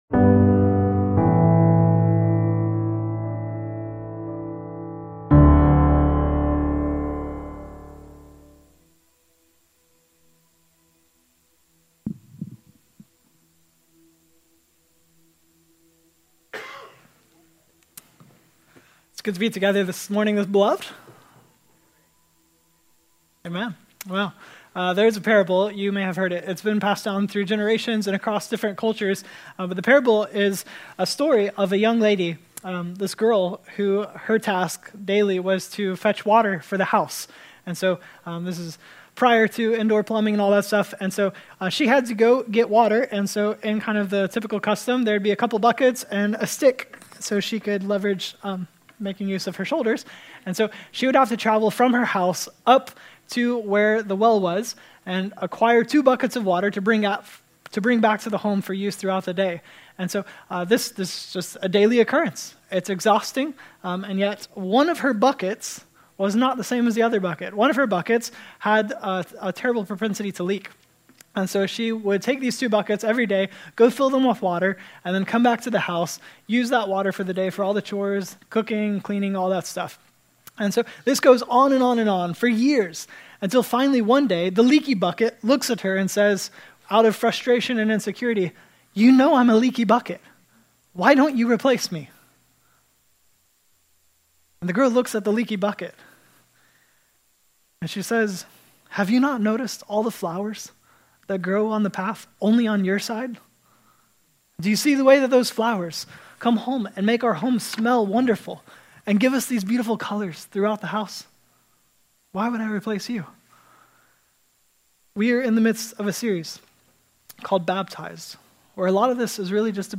5-5-24-Sermon-Audio.mp3